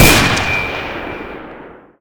new weapon sounds for the sniper rifle and the AT pistol
noscope.ogg